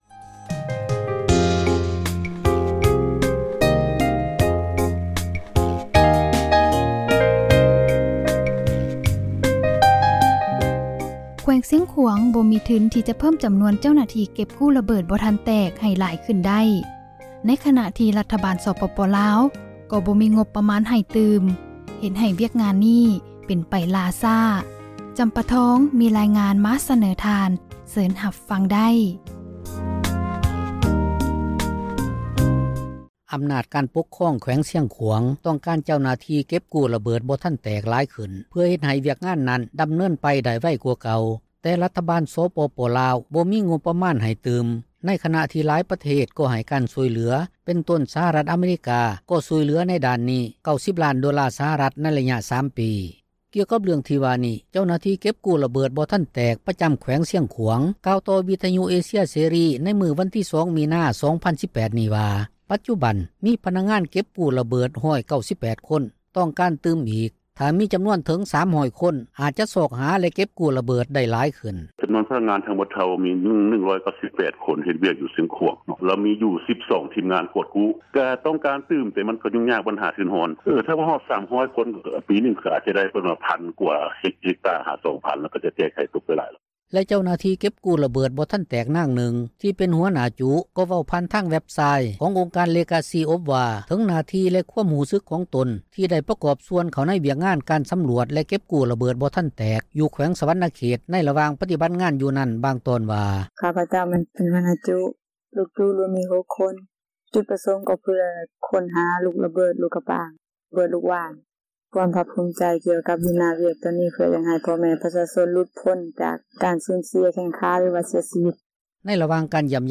ກ່ຽວກັບການເພີ່ມການຊ່ອຍເຫລືອທີ່ວ່ານີ້ ທ່ານ ບາຣັກ ໂອບາມາ ກ່າວຕໍ່ປະຊາຊົນລາວ ໃນມື້ວັນທີ 7 ກັນຍາ 2016 ຢູ່ນະຄອນຫລວງ ວຽງຈັນ ຕອນນຶ່ງວ່າ:
15 ທິມເກັບກູ້ຣະເບີດ ທີ່ກໍລັງປະຕິບັດໜ້າທີ່ຢູ່ ແຂວງ ສວັນນະເຂດ, ຄໍາມ່ວນ ແລະແຂວງອັດຕະປື ທີ່ມີມູນຄ່າທັງໝົດ 1,250,000 ໂດລາສະຫະຣັຖ. ມາດາມ ຣີນາ ປິດເຕີ ທູດສະຫະຣັຖອາເມຣິກາ ປະຈໍາລາວ  ກ່າວໃນພິທີມອບ-ຮັບ ເຄື່ອງຊ່ອຍເຫລືອ ນັ້ນວ່າ: